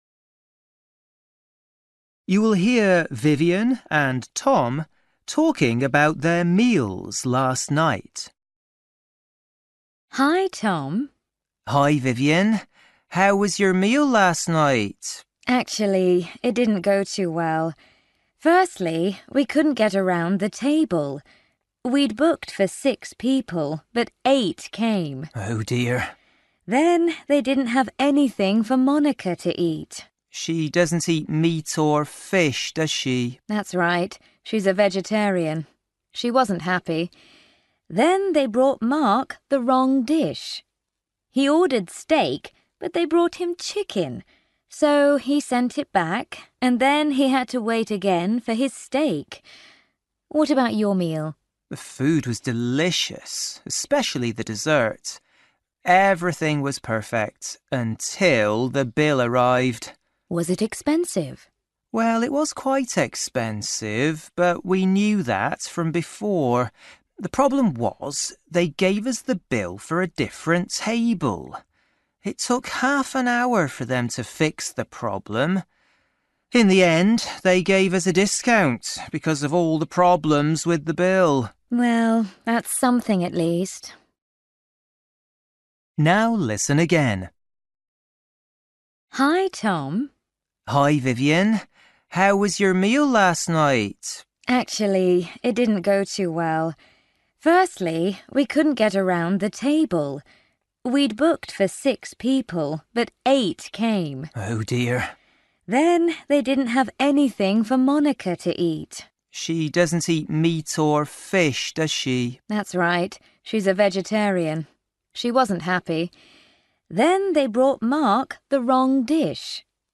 Bài tập trắc nghiệm luyện nghe tiếng Anh trình độ sơ trung cấp – Nghe một cuộc trò chuyện dài phần 14
You will hear Vivien and Tom talking about their meals last night.